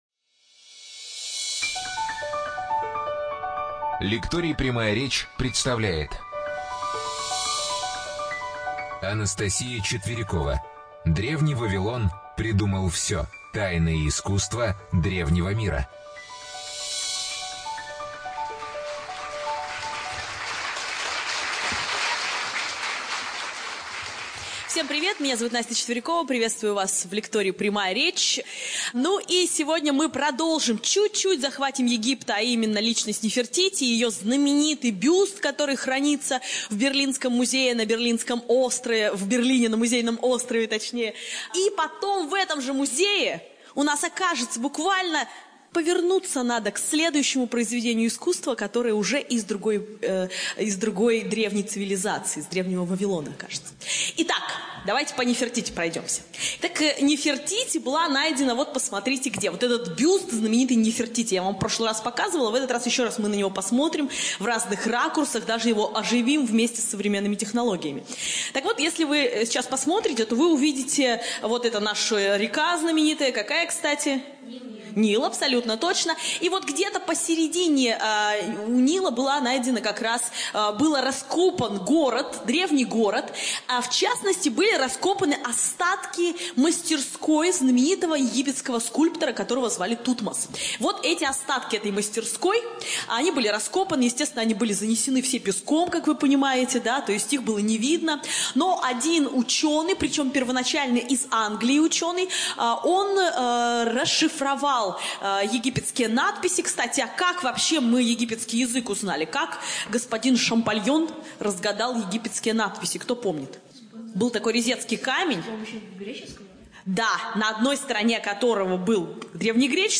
ЧитаетАвтор
Студия звукозаписиЛекторий "Прямая речь"